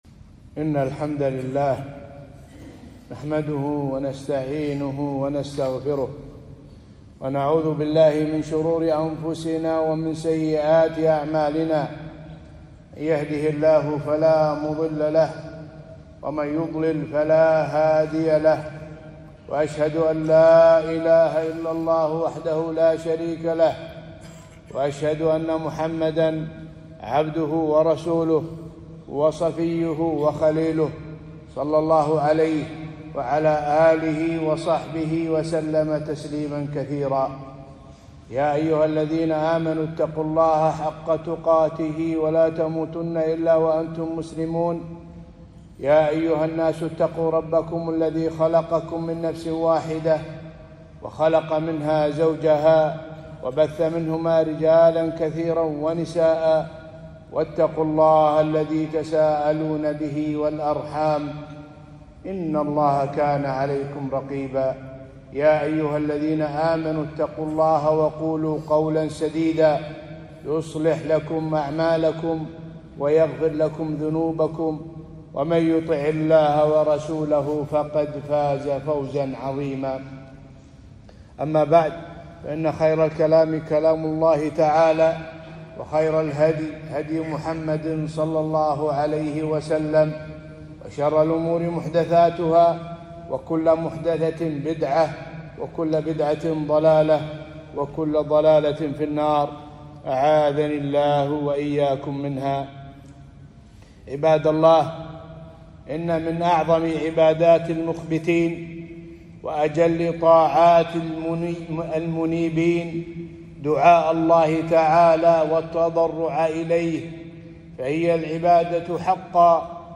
خطبة - الدعاء سلاح المؤمن في الأزمات